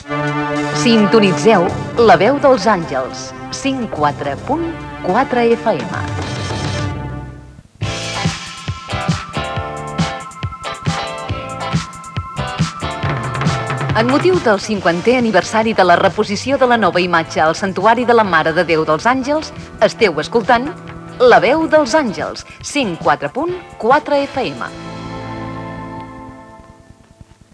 Inidcatiu de l'emissora